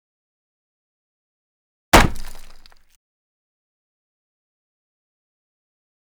43 Ax Impact Into Wood #2.wav